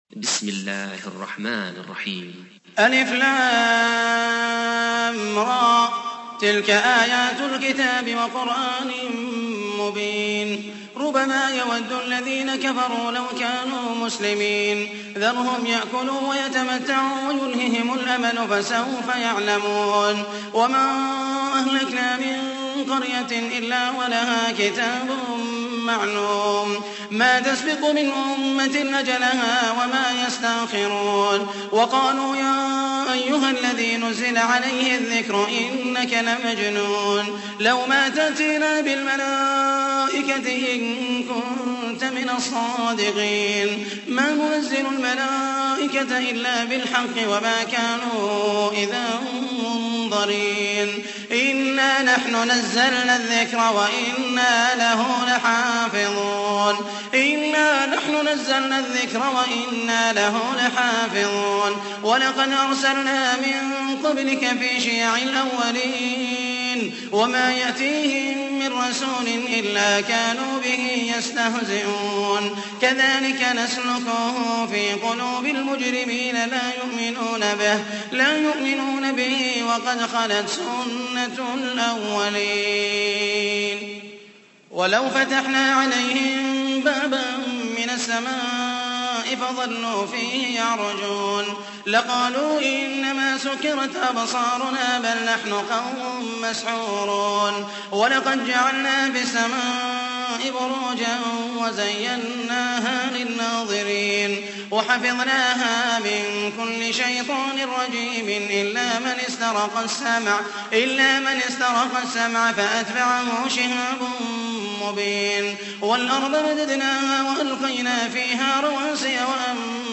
تحميل : 15. سورة الحجر / القارئ محمد المحيسني / القرآن الكريم / موقع يا حسين